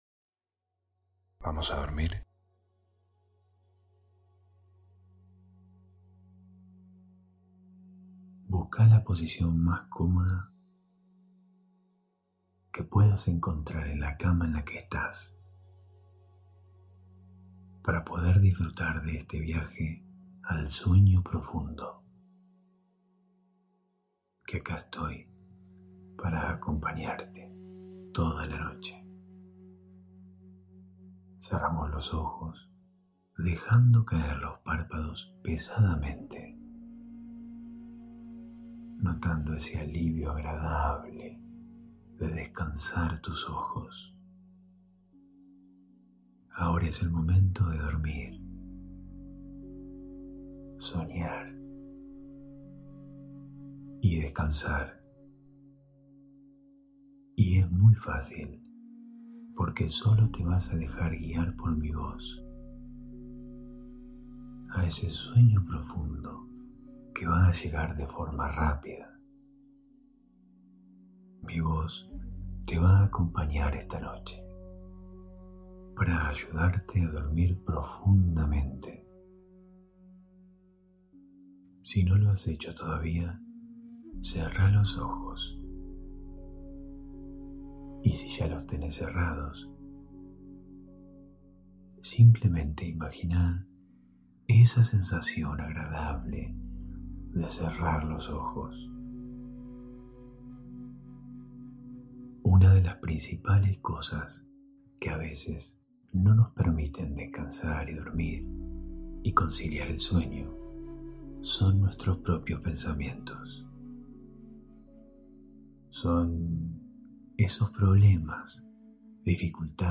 Hipnosis para Dormir ✨
[Altamente recomendable escucharlo con auriculares ] Hosted on Acast.